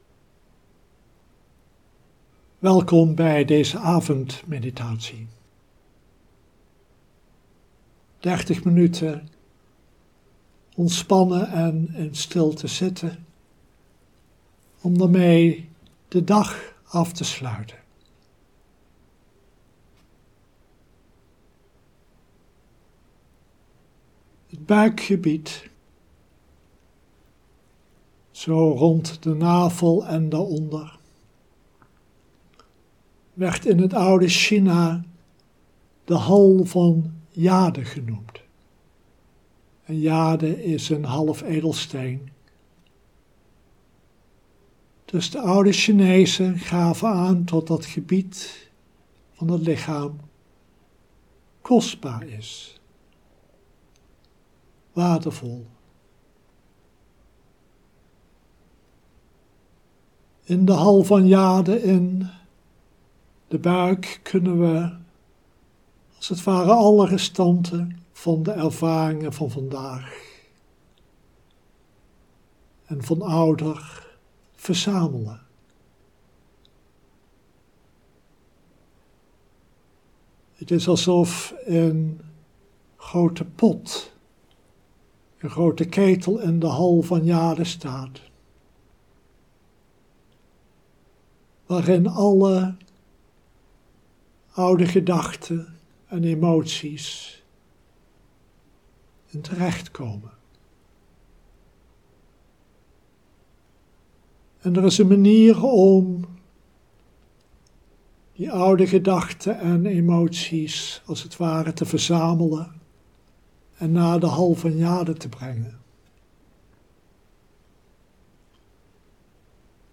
Livestream opname